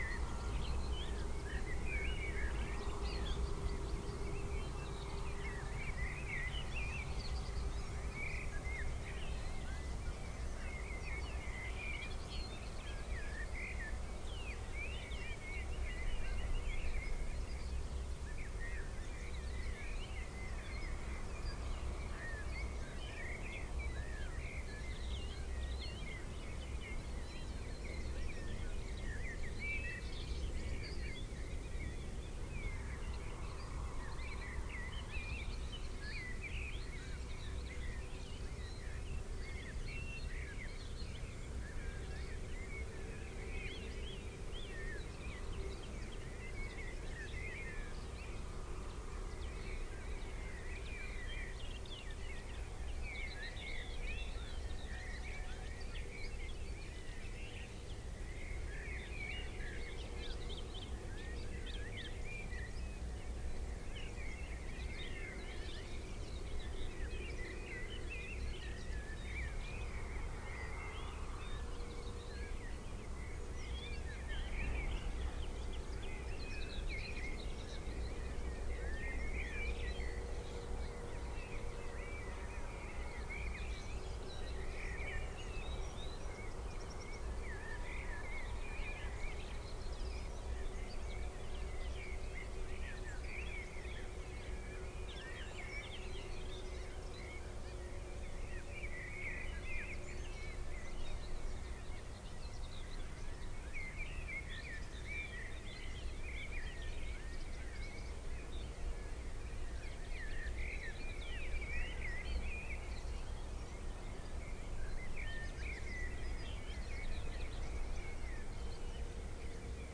Corvus corone
Columba palumbus
Emberiza citrinella
Phylloscopus collybita
Alauda arvensis
Turdus merula